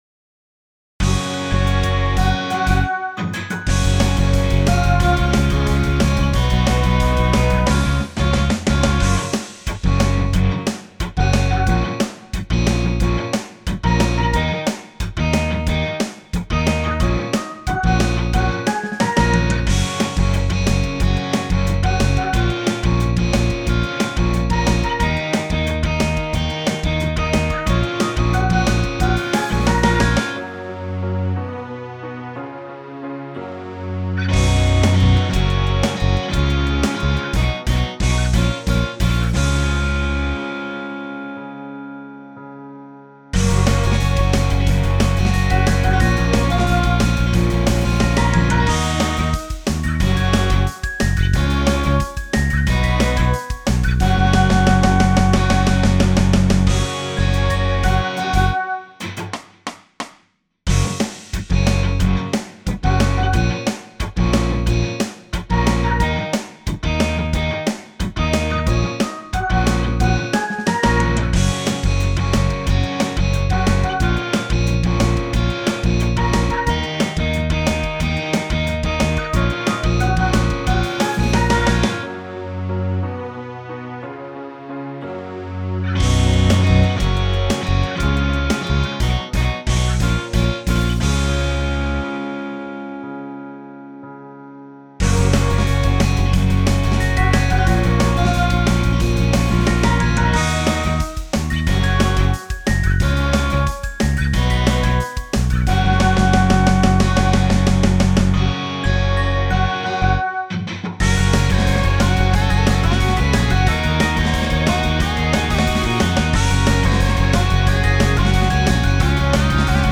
インスト音源 / コード譜 配布